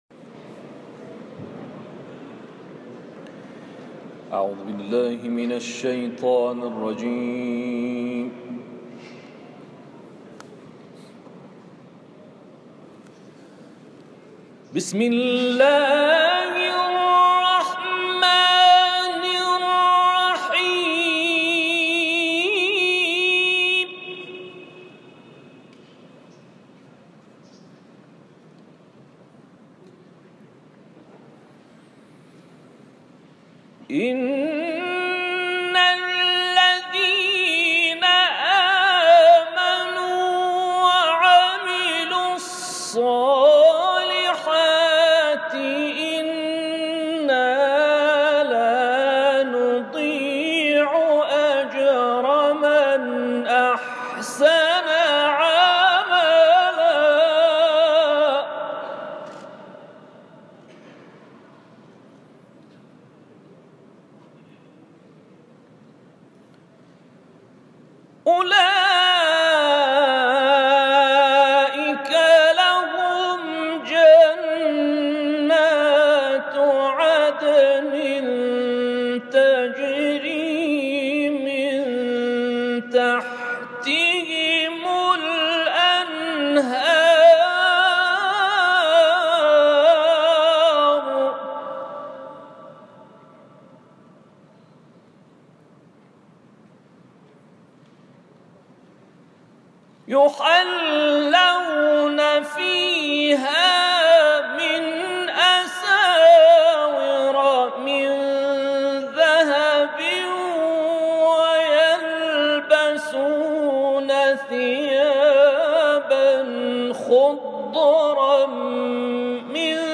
تلاوت در کانال‌های قرآنی/